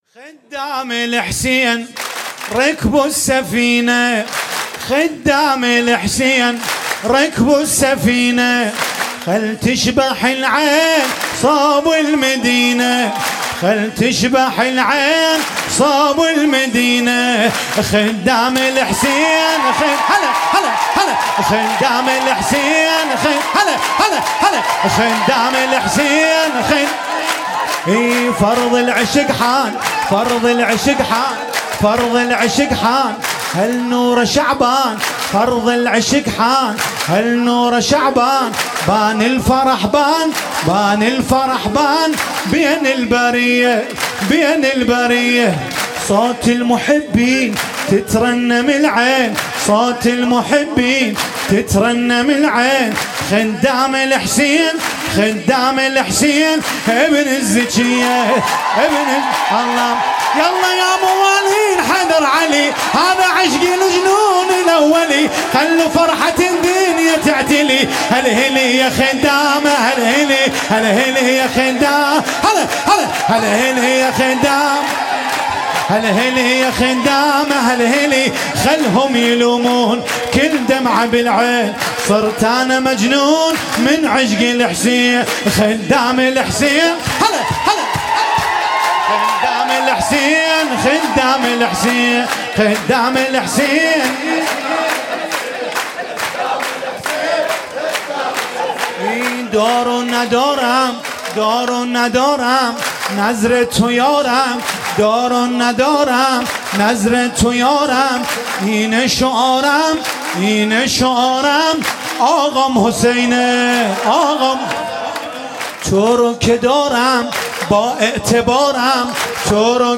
ولادت انوار کربلا 96 - هیئت محبان الائمه - شور - خدام الحسین رکبوا السفینه